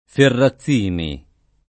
[ ferra ZZ& ni ]